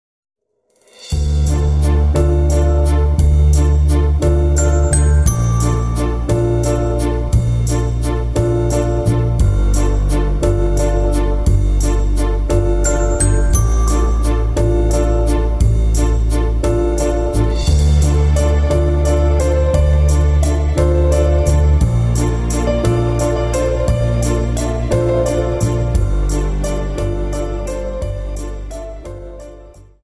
Viennese Waltz 58 Song